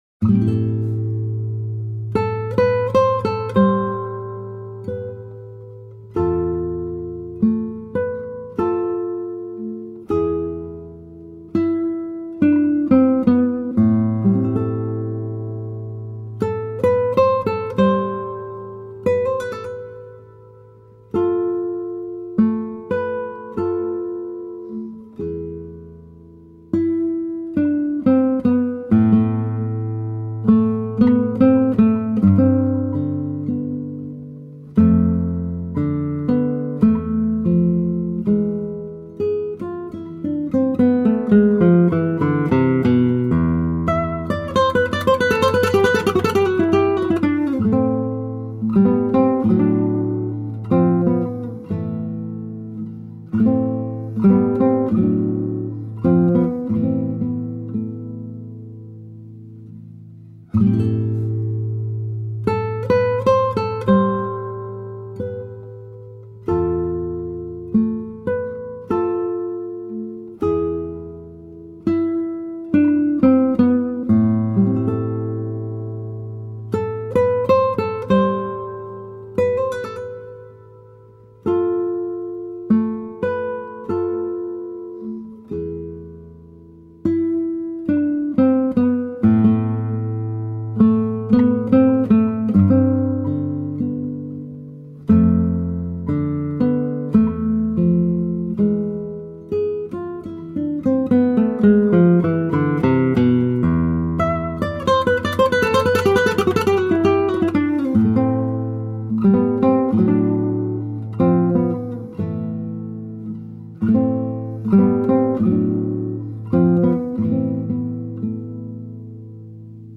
Outstanding classical guitar.